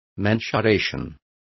Complete with pronunciation of the translation of mensurations.